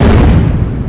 boom1.mp3